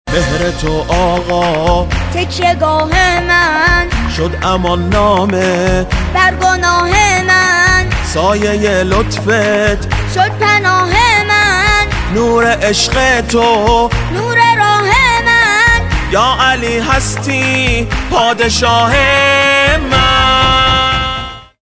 رینگتون موبایل غدیری و با نشاط
(باکلام فارسی)